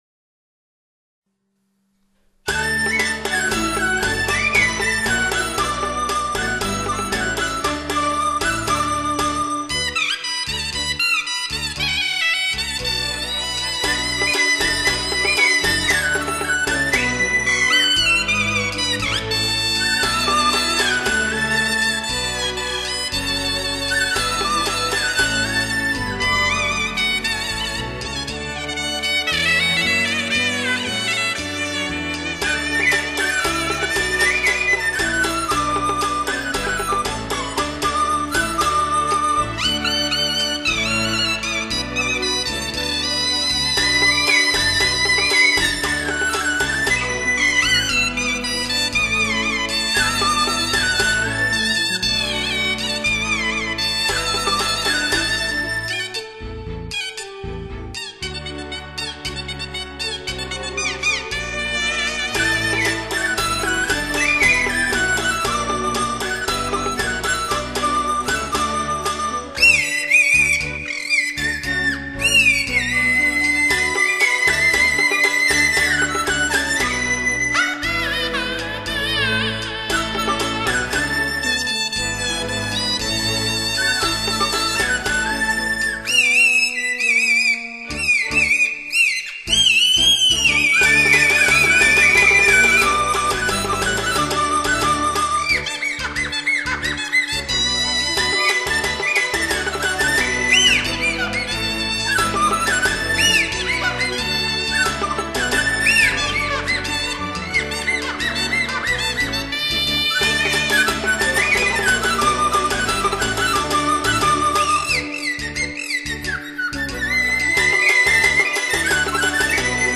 民间乐曲） 唢呐独奏
乐曲用吹咔和口哨生动地描绘了这一场面。